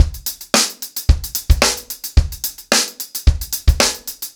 TupidCow-110BPM.31.wav